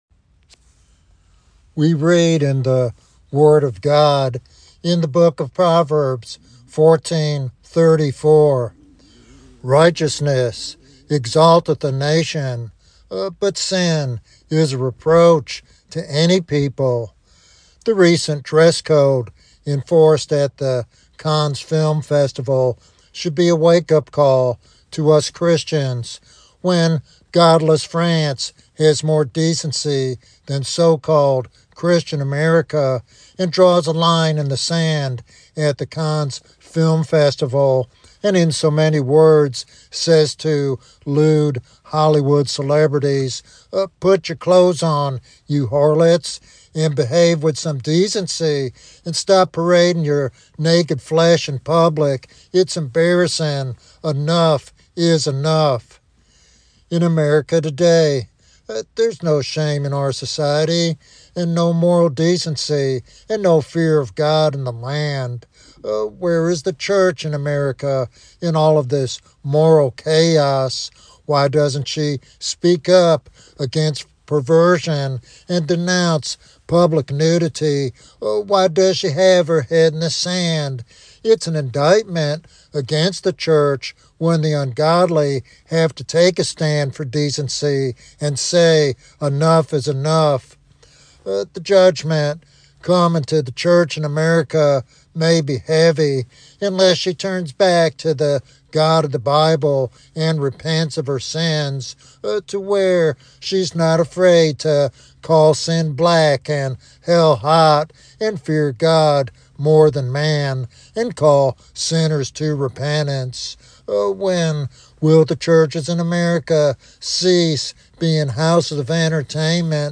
The sermon addresses the lack of moral decency and the church's failure to confront sin in society.